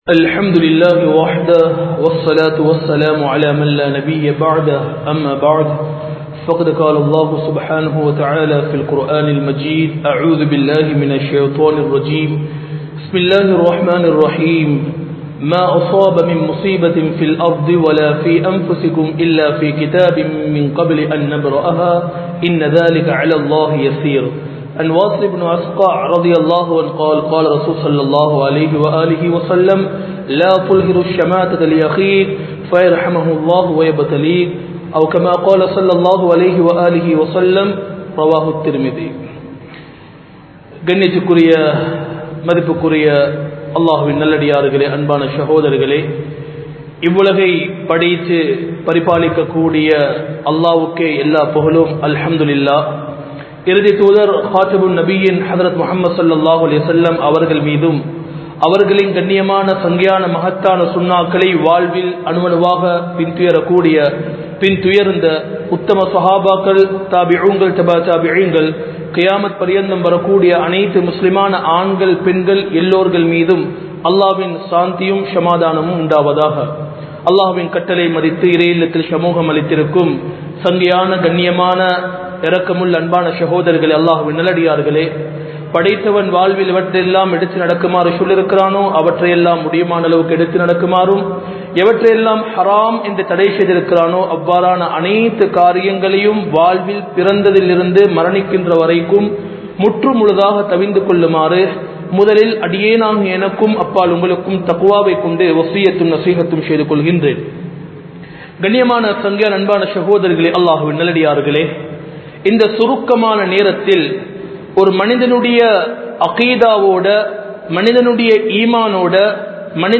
Thottru Noaium Manitha Neayamum (தொற்று நோயும் மனித நேயமும்) | Audio Bayans | All Ceylon Muslim Youth Community | Addalaichenai
Colombo 04, Majma Ul Khairah Jumua Masjith (Nimal Road)